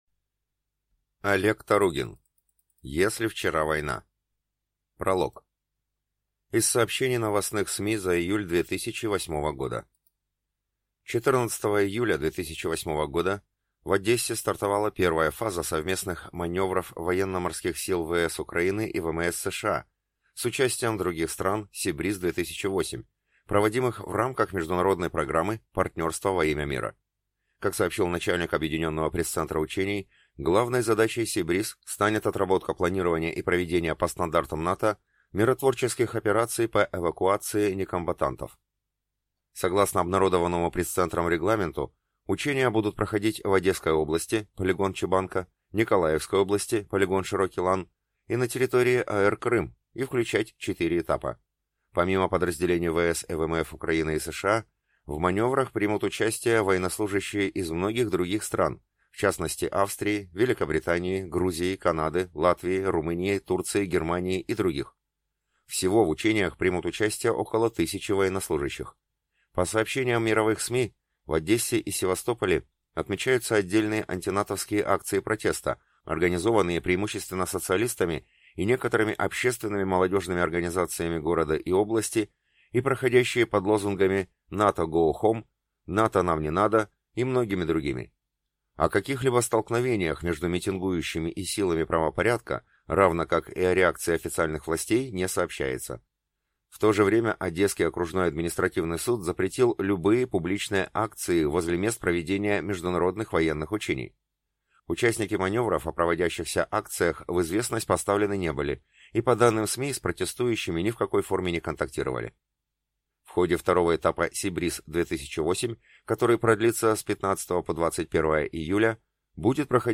Аудиокнига Если вчера война…